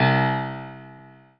piano-ff-16.wav